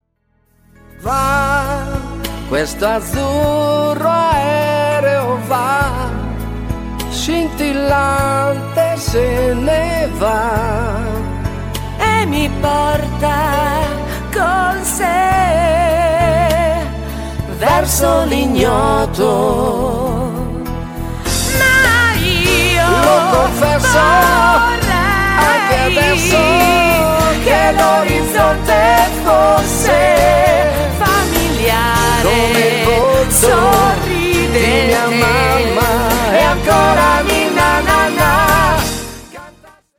SLOW  (3.06)